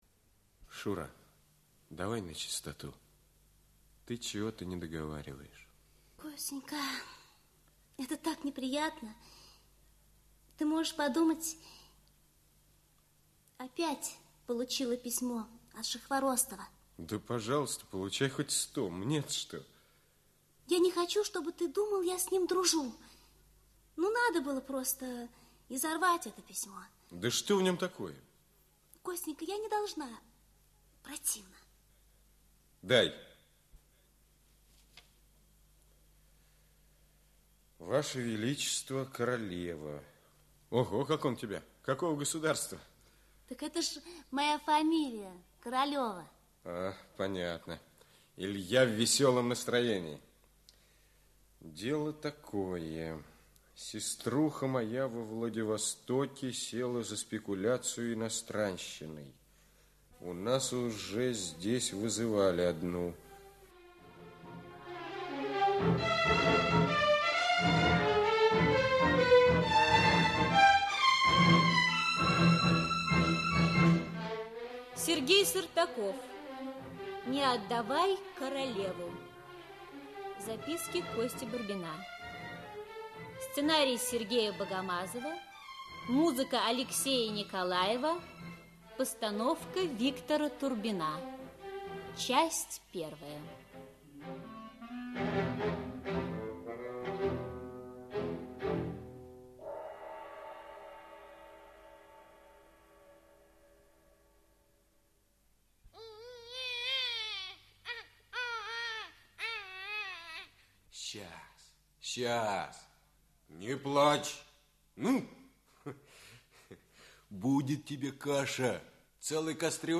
Часть 1 Автор Сергей Сартаков Читает аудиокнигу Олег Табаков.